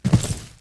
FallImpact_Concrete 04.wav